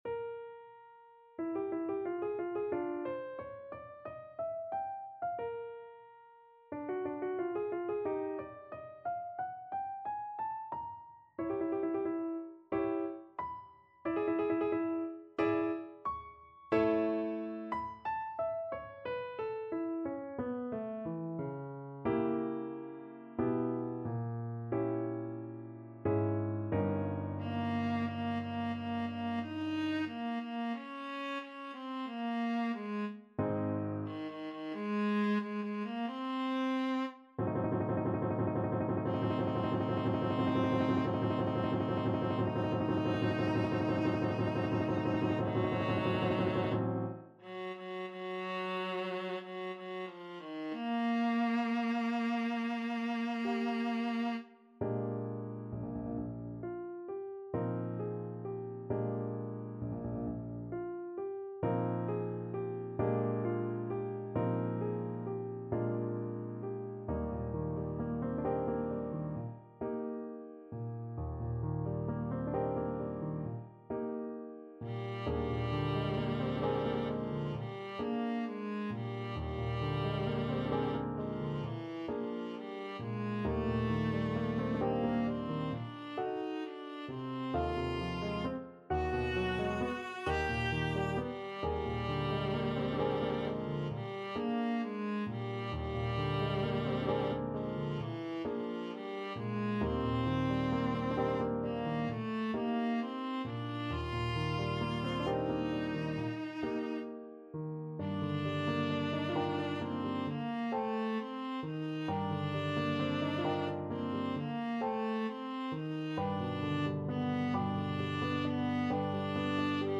Free Sheet music for Viola
Viola
Eb major (Sounding Pitch) (View more Eb major Music for Viola )
4/4 (View more 4/4 Music)
Moderato =90
Classical (View more Classical Viola Music)